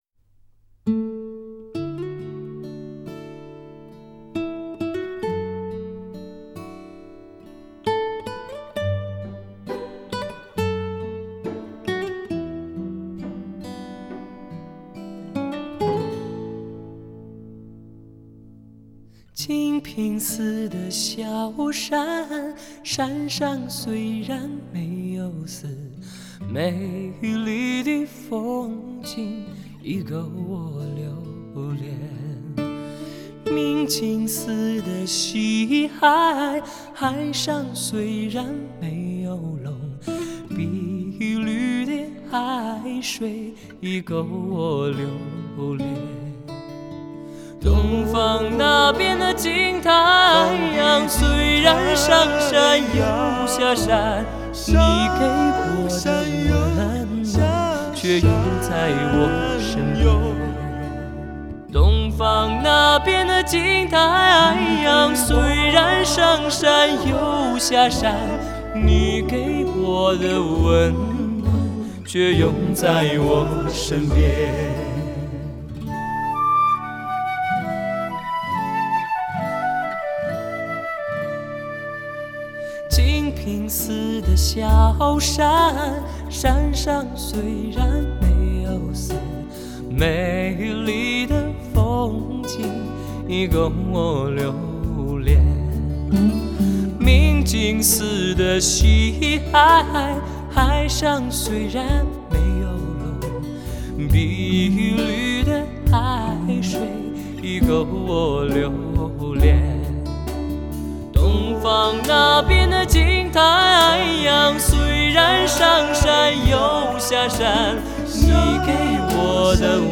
录音棚中，同一首歌，4个俊朗的男生让我听见四色彩虹。